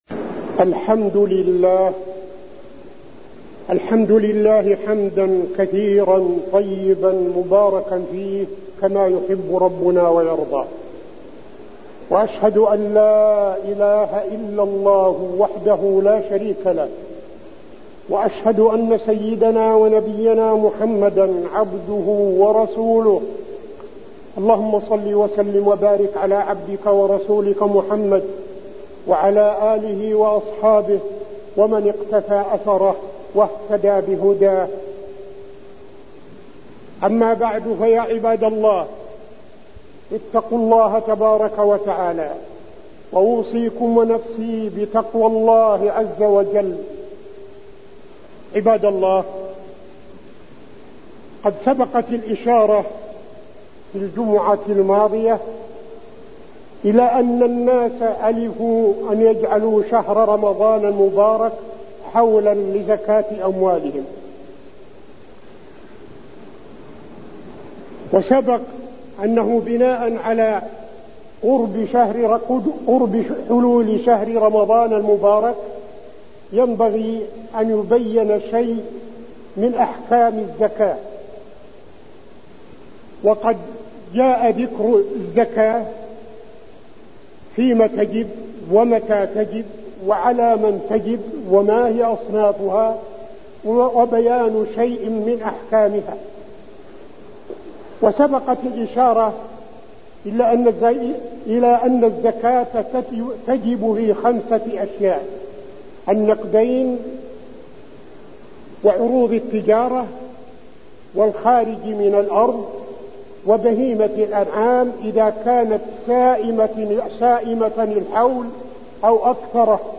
تاريخ النشر ٢٨ شعبان ١٤٠٨ هـ المكان: المسجد النبوي الشيخ: فضيلة الشيخ عبدالعزيز بن صالح فضيلة الشيخ عبدالعزيز بن صالح الزكاة وأحكامها2